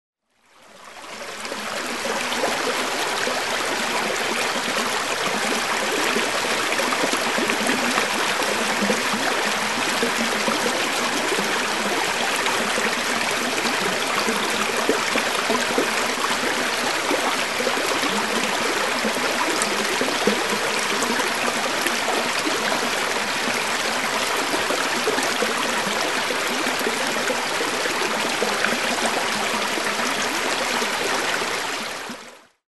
Шум маленького водопада в устье реки